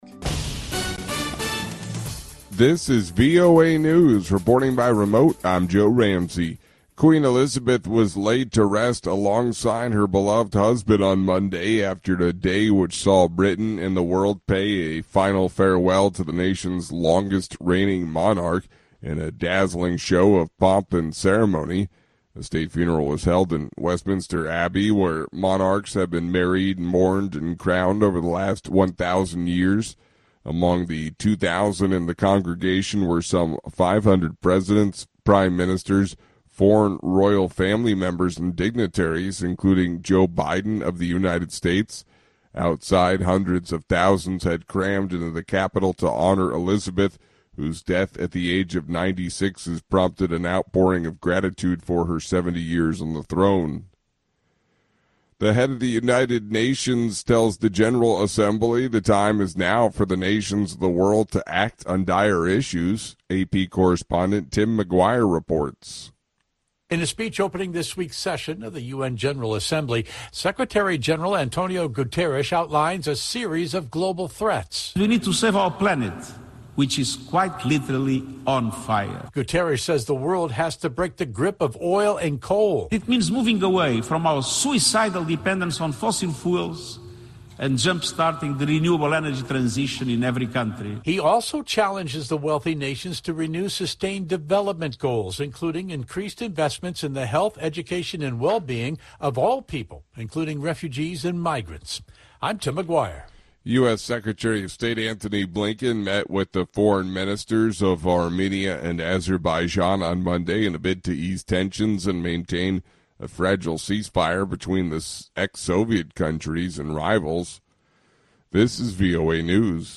VOA 2-min news の音声はかなり速いので、プレイヤーの ボタンを押して、ゆっくりにしてシャドウイングを試してみても効果があります。